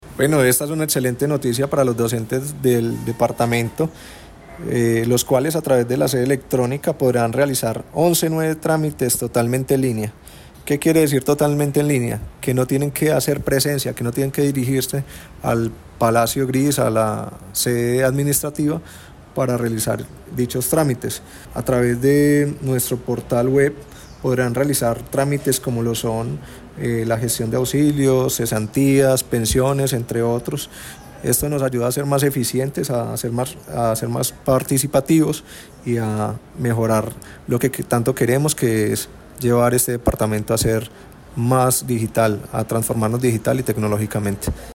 El anuncio fue realizado por el secretario de las TIC, Alexánder Vásquez Hernández, quien destacó la importancia de esta innovación: “Esta es una excelente noticia para los docentes del departamento, los cuales, a través de la sede electrónica, podrán realizar 11 nuevos trámites totalmente en línea.